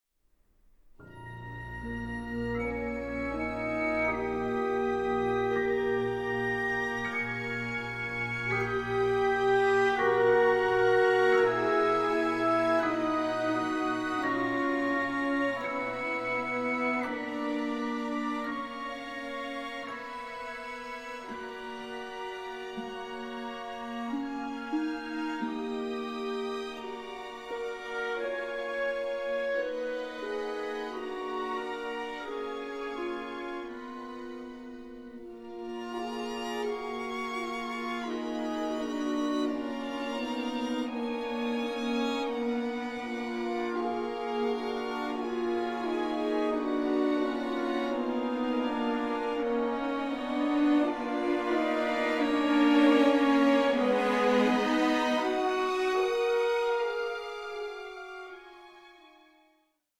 With orchestral textures both radiant and unsettling